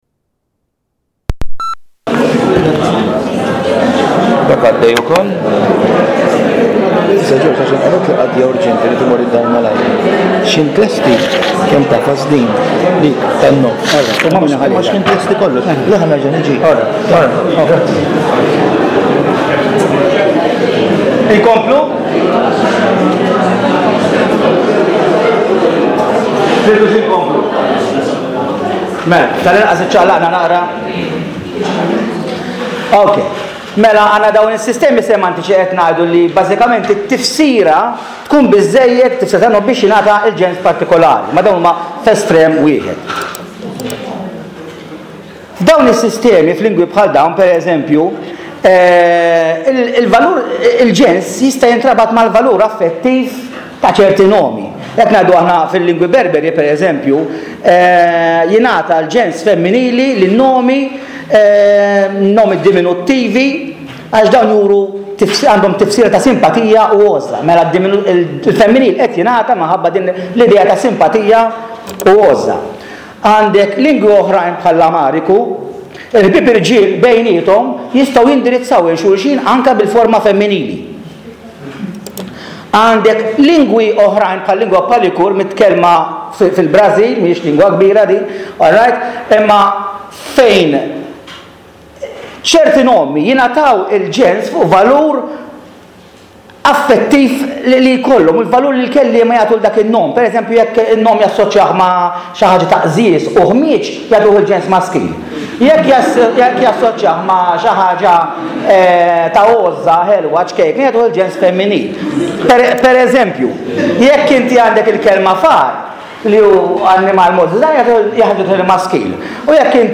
Taħdita organizzata mill-Akkademja tal-Malti u d-Dipartiment tal-Malti tal-Università, nhar l-Erbgħa, 28 ta’ Marzu 2012, fis-6.30, l-Università ta’ Malta.